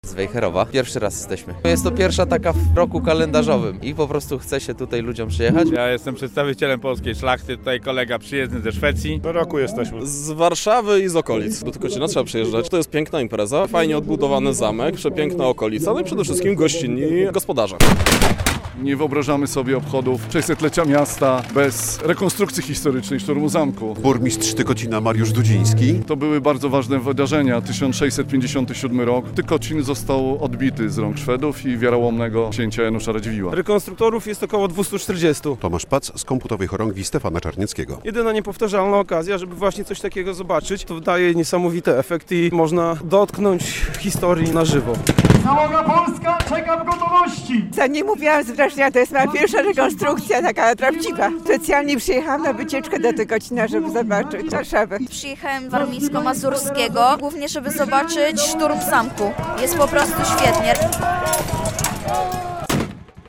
Szturm na zamek w Tykocinie - przyjechali rekonstruktorzy i widzowie z całego kraju - relacja